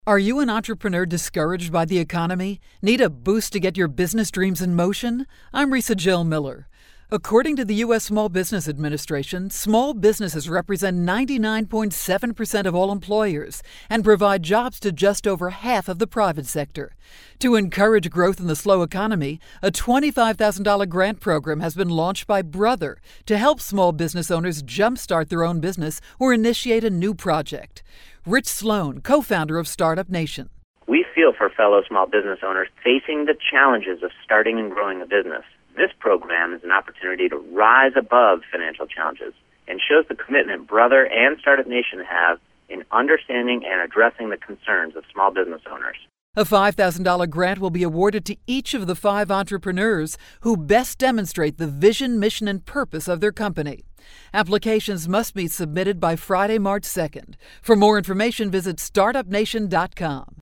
January 9, 2012Posted in: Audio News Release